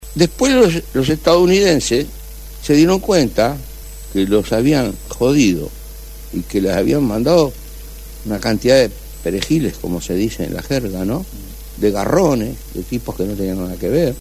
En entrevista con Telebuendía, Huidobro aseguró que los detenidos en el centro de detención de Estados Unidos fueron el producto de una "caza de brujas", y que el centro de detención, "está lleno de perejiles, es una cárcel clandestina, llena de garrones".